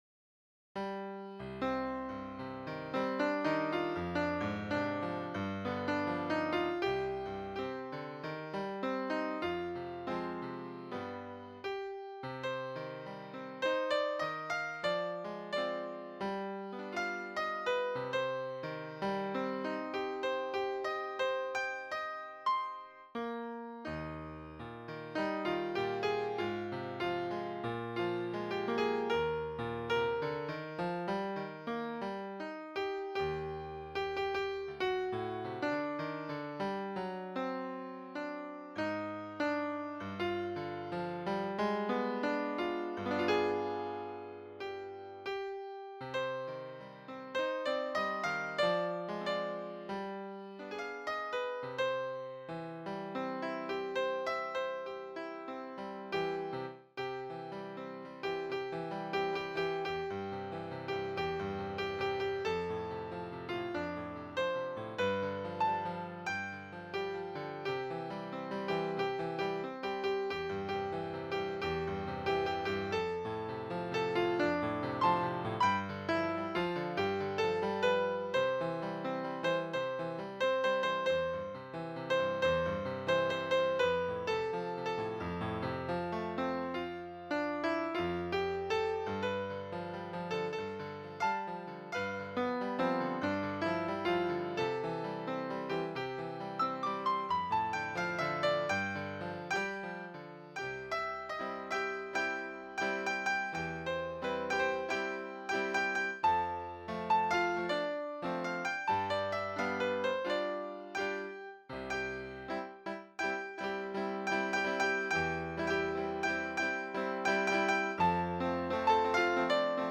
Partitura para piano / Piano score (pdf)
Partitura sencilla para piano/Easy piano score (pdf).